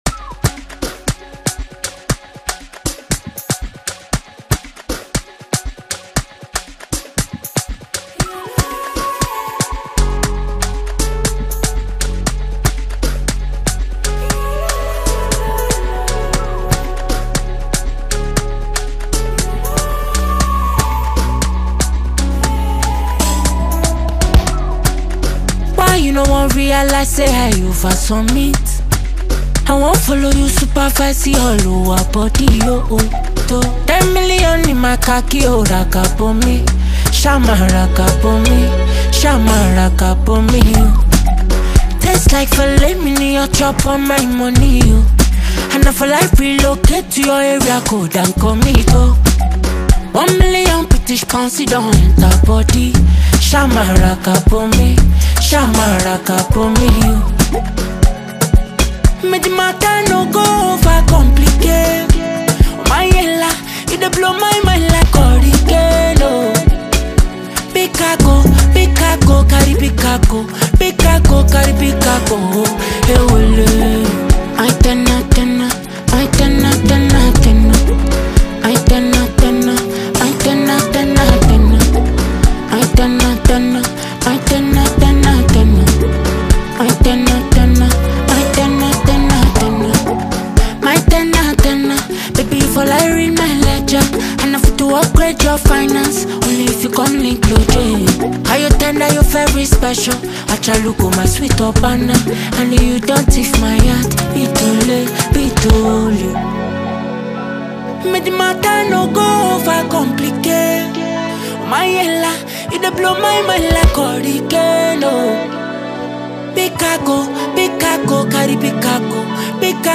Get this energizing song